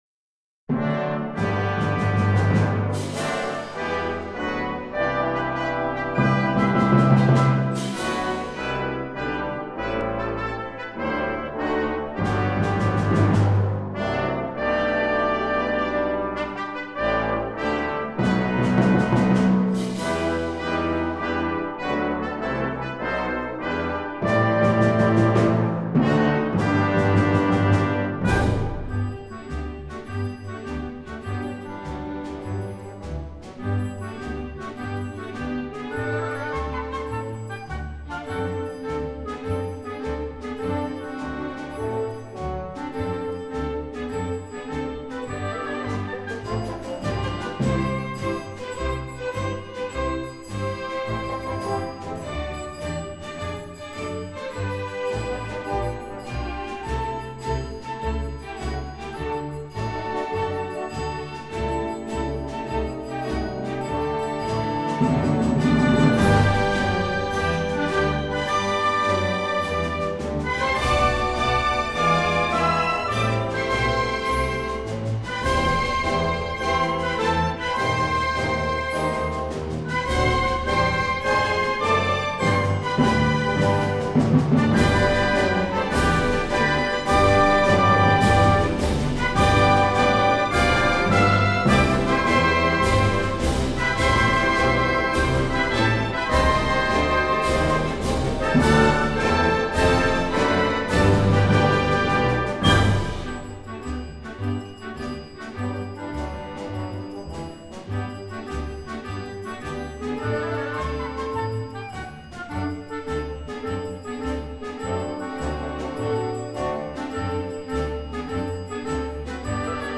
Inno nazionale